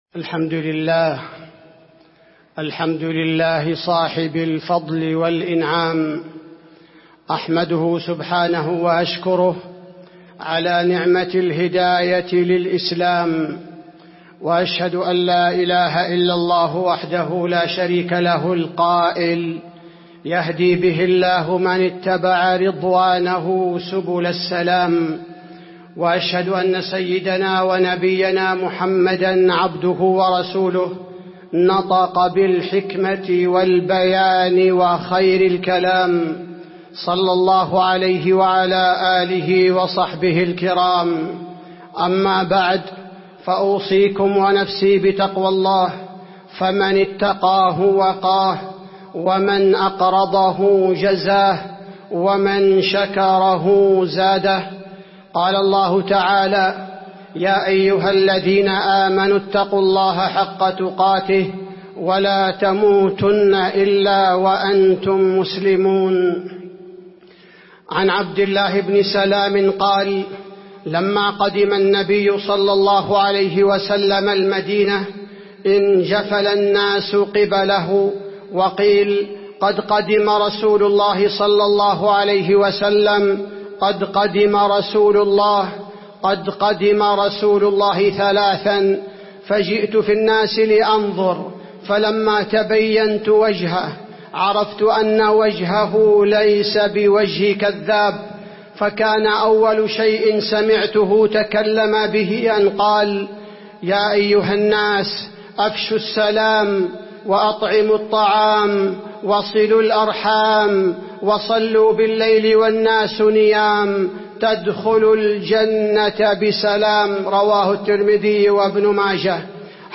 تاريخ النشر ١٤ ربيع الثاني ١٤٤٣ هـ المكان: المسجد النبوي الشيخ: فضيلة الشيخ عبدالباري الثبيتي فضيلة الشيخ عبدالباري الثبيتي تدخلوا الجنة بسلام